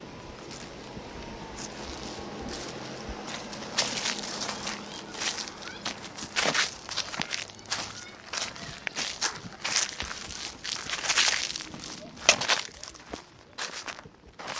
描述：快速地碾碎一片叶子。
Tag: 叶紧缩 WAV 秋天 叶子 速度快 死叶